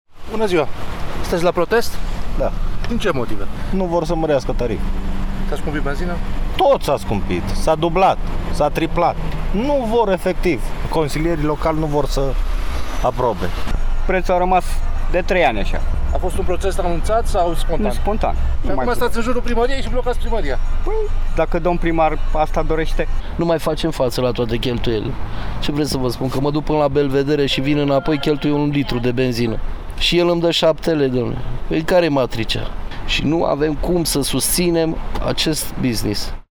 Șoferii de taxi sunt nemulțumiți de tarifele practicate în acest moment care, spun ei, nu le acoperă nici măcar cheltuielile:
VOX-TAXIMETRISTI.mp3